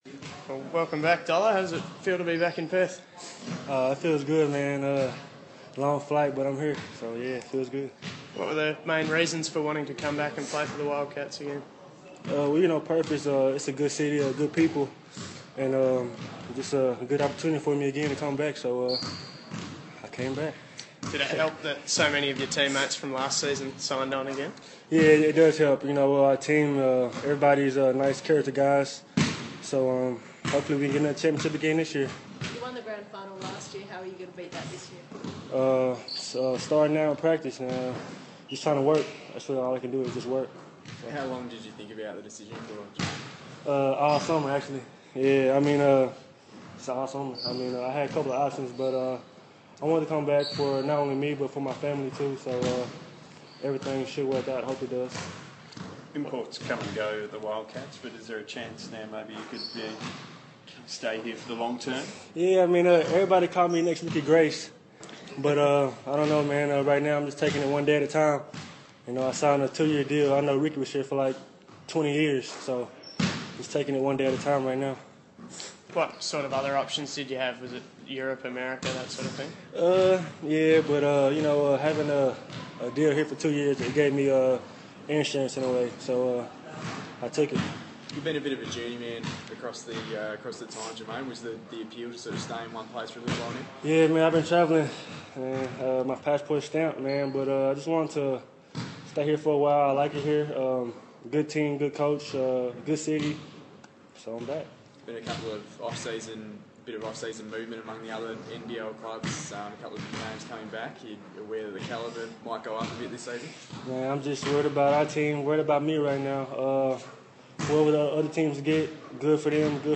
speaks to the media after re-signing with the club.